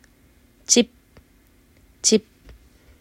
チㇷ゚                   　　cip         舟